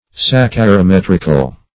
Search Result for " saccharimetrical" : The Collaborative International Dictionary of English v.0.48: Saccharimetrical \Sac`cha*ri*met"ric*al\, a. Of or pertaining to saccharimetry; obtained by saccharimetry.